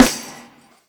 zfg_snr.wav